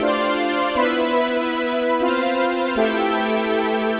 Index of /musicradar/80s-heat-samples/120bpm
AM_VictorPad_120-A.wav